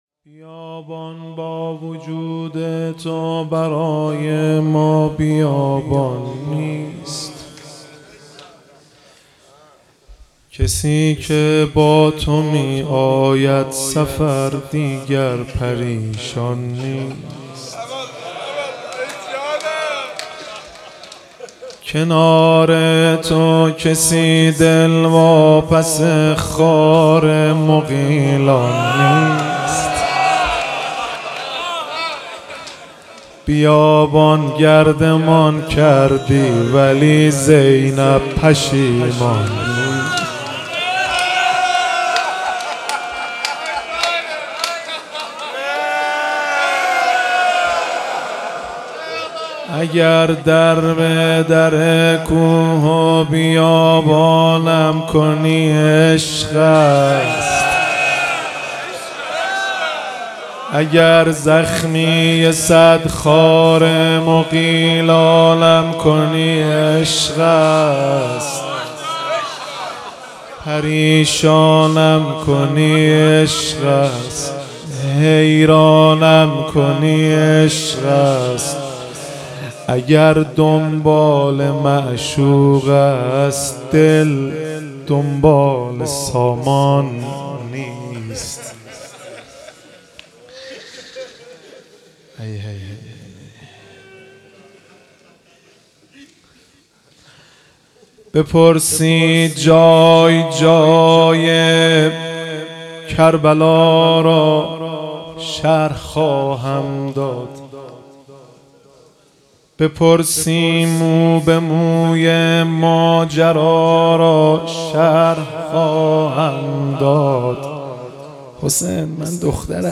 تولید شده: هیئت ثارالله زنجان